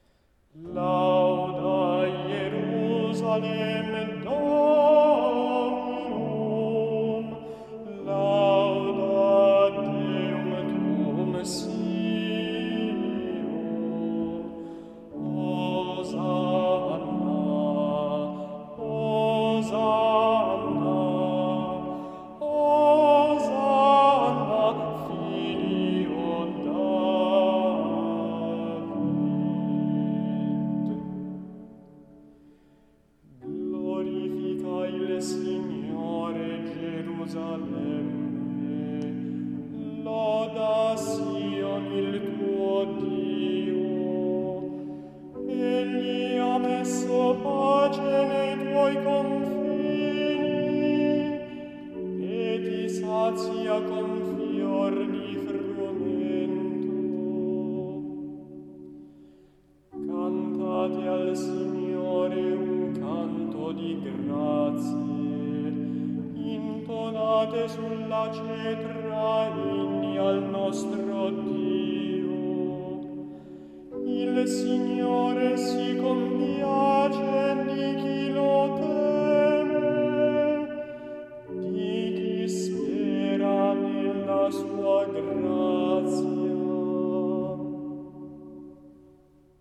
Il ritornello, tratto dal Salmo 147 (148) è di autore ignoto; le strofe provengono dal repertorio di Lourdes. È indicato come canto generico sulla Chiesa.
Audio esecuzione a cura degli animatori musicali del Duomo di Milano